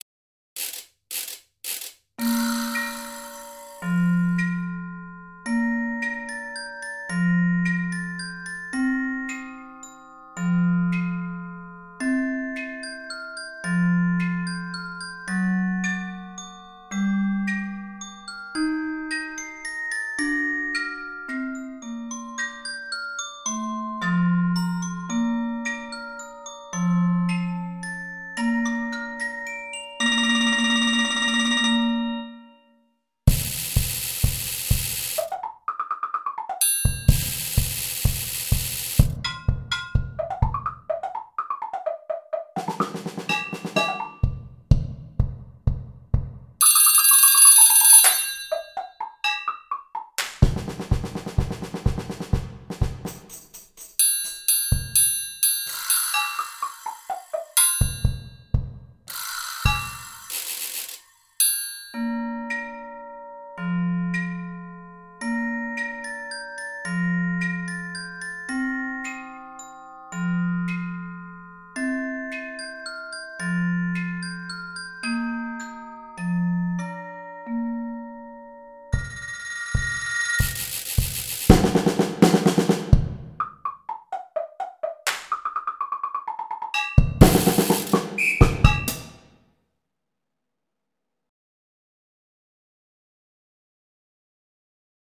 Genre: Percussion Ensemble
Player 1: Vibraslap, Temple Blocks
Player 3: Chimes, Bass Drum, Police Whistle